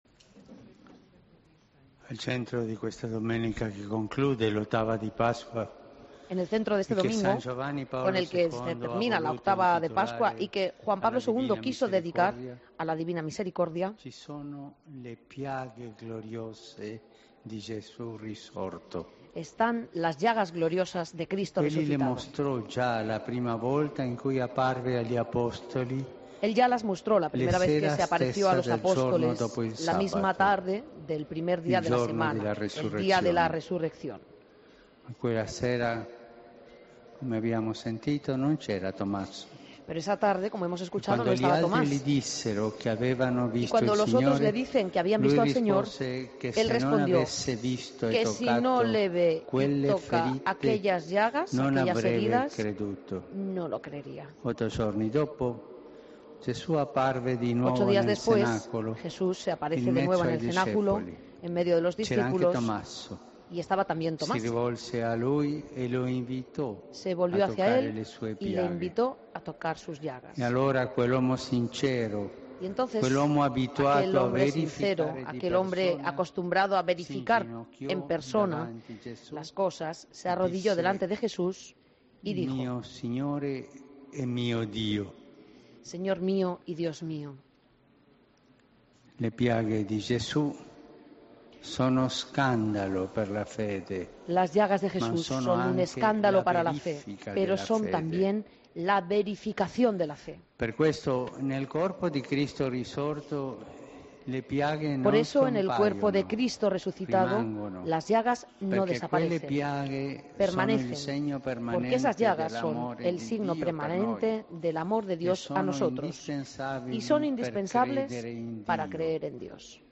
Homilía del Domingo 27 de Abril de 2014